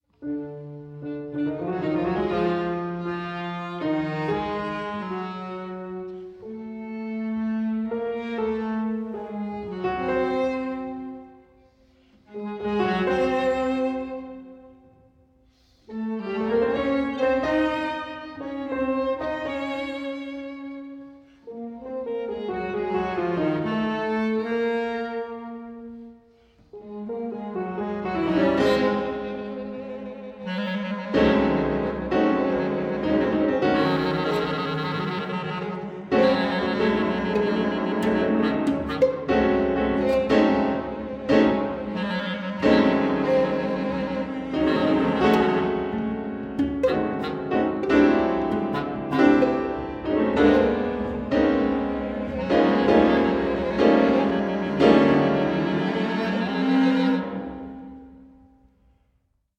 violin
cello
piano
clarinet